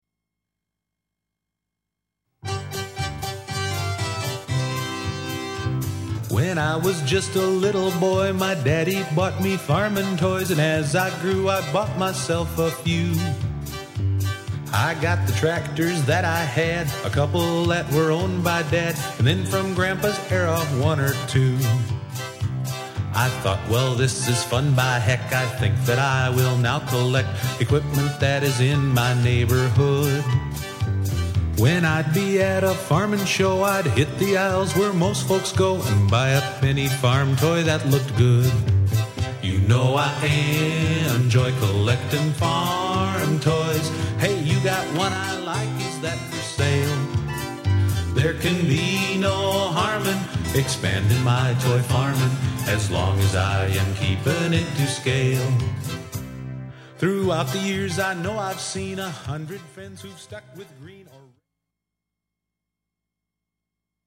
Real Farmers making Real Music using Real Tractors